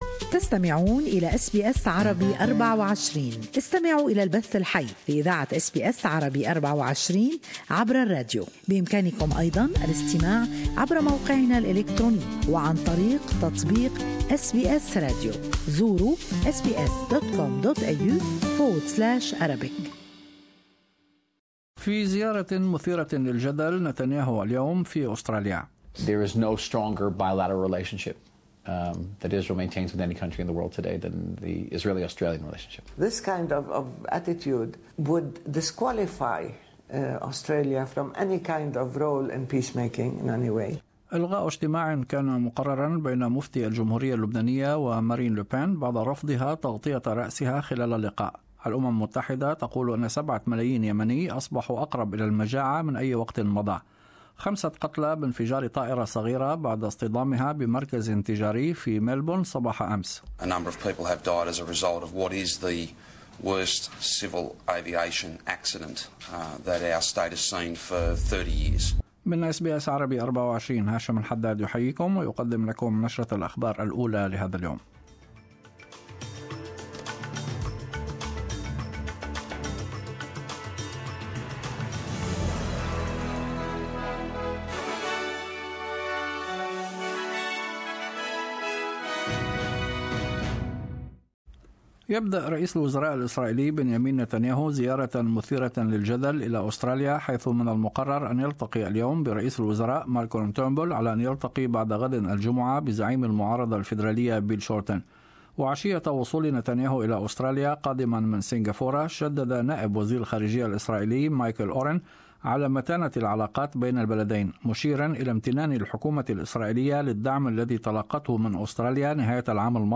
News Bulletin